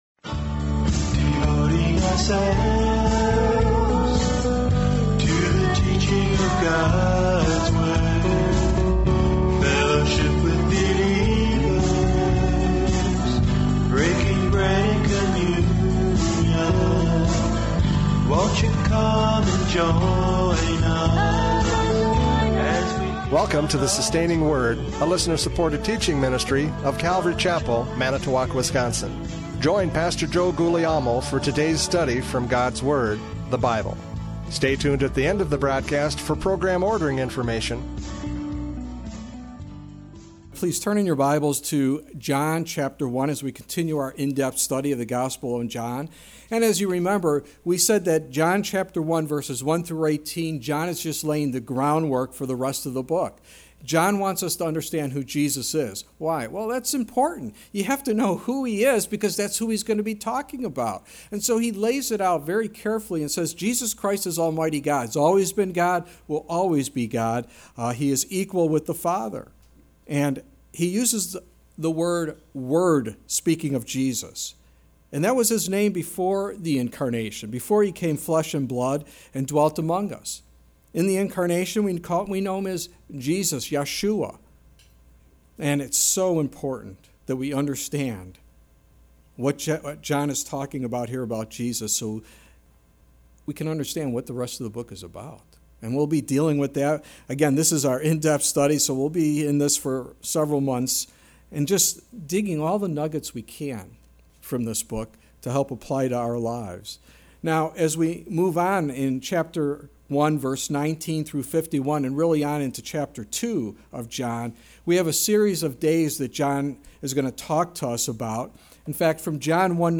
John 1:35-51 Service Type: Radio Programs « John 1:29-34 John the Baptist’s Testimony!